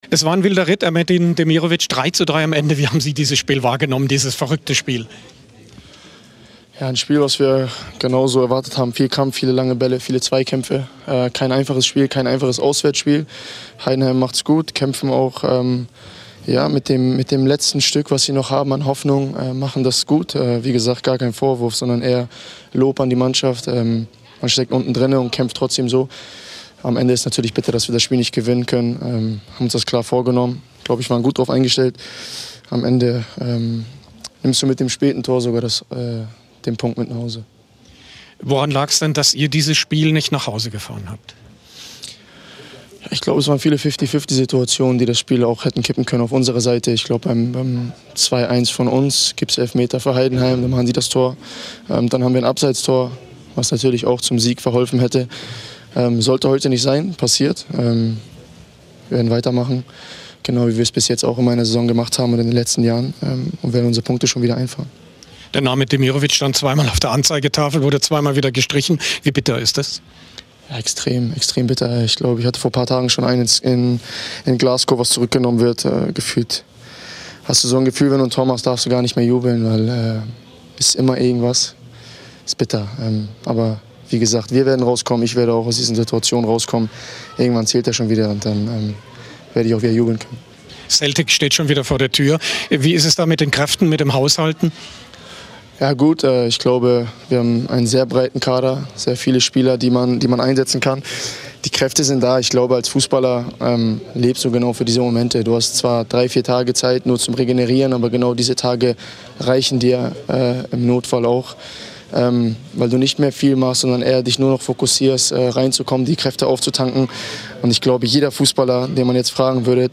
Ex-Nationalspieler Cacau mit SWR Sport-Moderator Tom Bartels
Ex-Nationalspieler Cacau war am Sonntag zu Gast in der TV-Sendung SWR Sport. Der früher VfB-Profi ist immer noch nah dran an den Stuttgartern und hat sich unter anderem auch zu den strittigen Schiedsrichterentscheidungen im Spiel gegen Heidenheim geäußert.